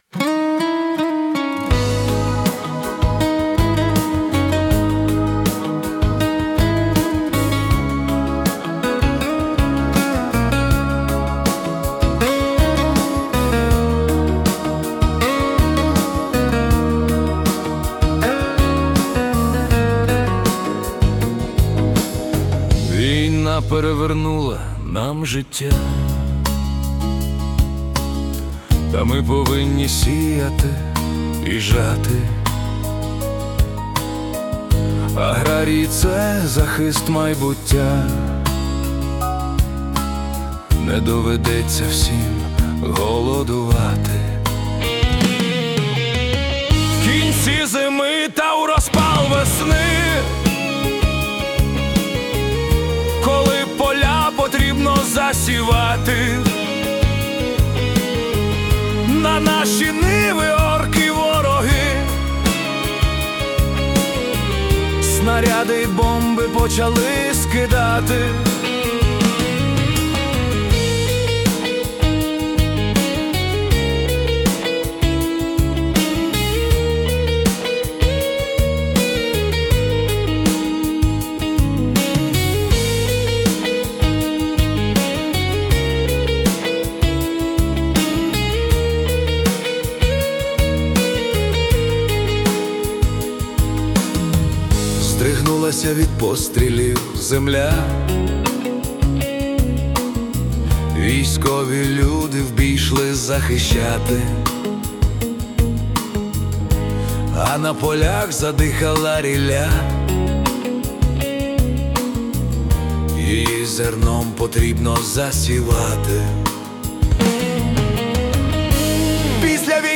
🎵 Жанр: Folk Ballad
це фолк-балада (80 BPM)
Фінал пісні сповнений оптимізму та рішучості.